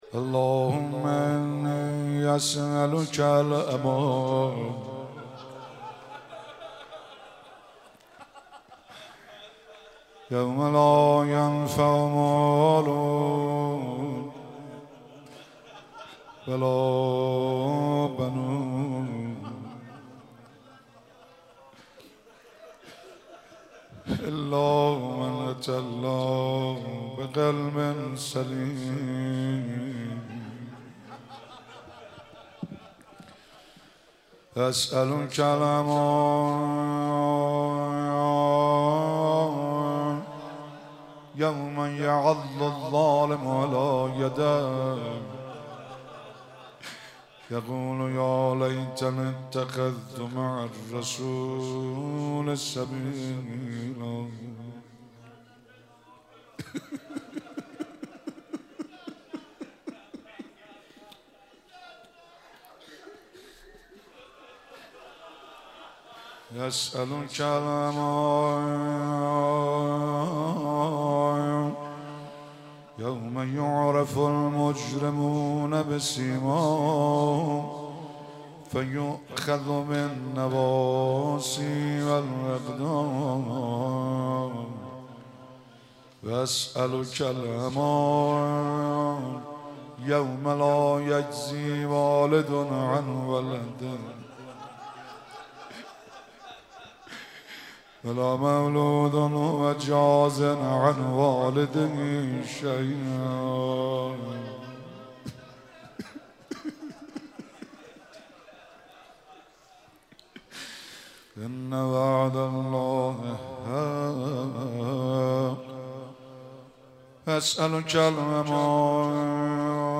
مراسم مناجات خوانی و احیای شب نوزدهم ماه رمضان 1444